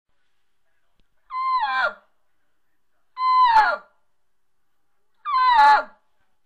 bullchirp
Bull Elk Sounds The Bull Elk make the most magnificent and powerful sounds. This is done to chase off, challenge, and establish dominance over the other Bull Elk.
bull_chirp.wma